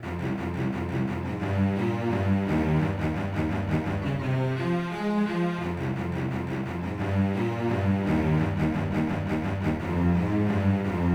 cellolong.wav